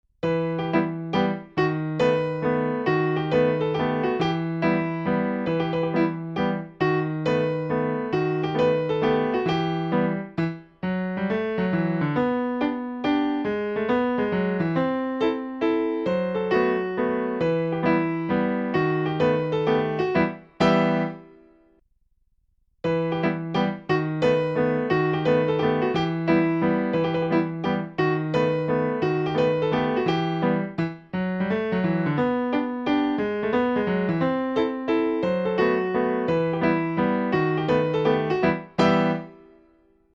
Mazurka_vitesseLentepuisNormale
Mazurka_vitesseLentepuisNormale.mp3